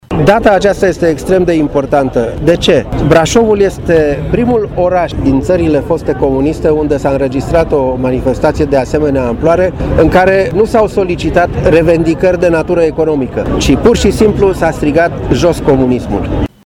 La manifestarea de la Brașov a fost prezent și istoricul și scriitorul Marius Oprea, care, în completarea programului dedicat evenimentului își va lansa cartea ,,Ziua care nu se uită. Revolta brașovenilor din 15 noiembrie 1987”: